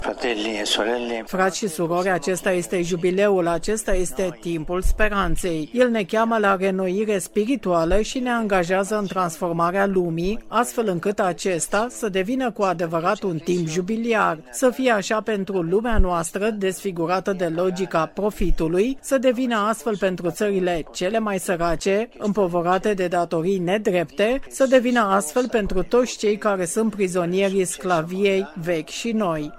Aproximativ 100.000 de persoane au asistat, ulterior, la slujba de Ajunul Crăciunului, oficiată de Suveranul Pontif: